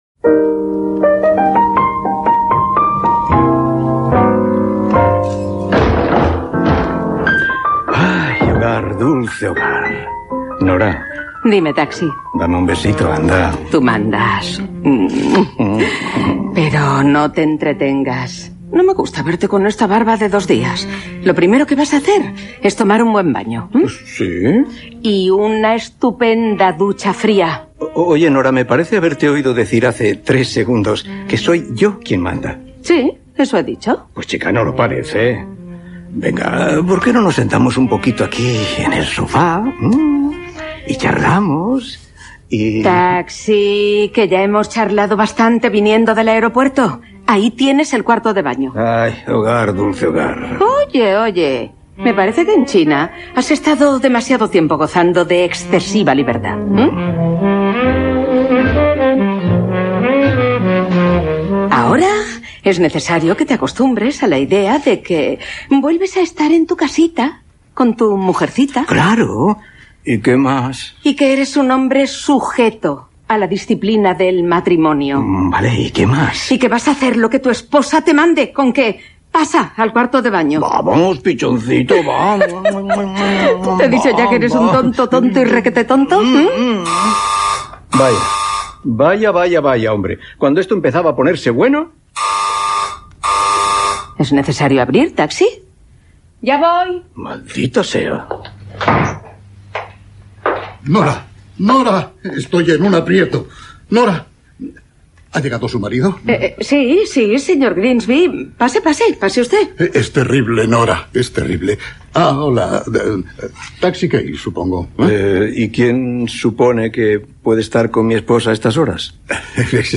La ventana: recreació de Taxi Key - Ràdio Barcelona, 2008
Àudio: arxiu històric de Ràdio Barcelona